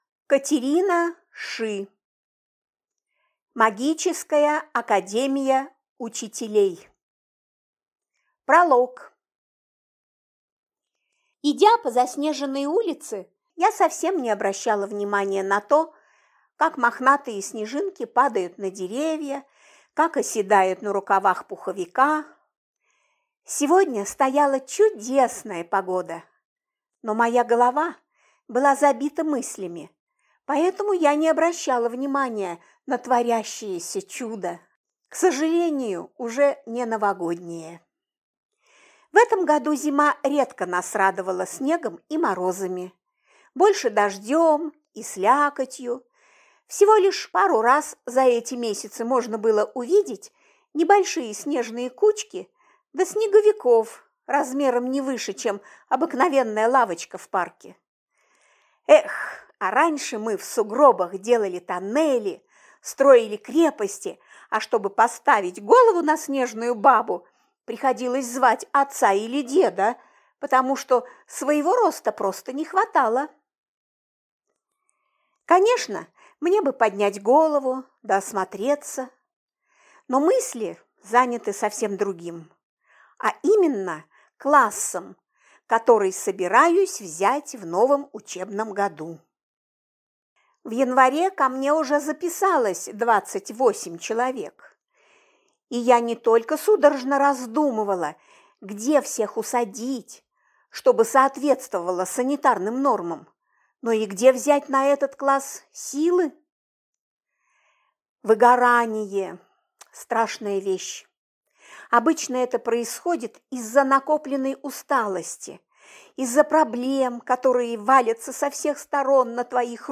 Аудиокнига Магическая академия учителей | Библиотека аудиокниг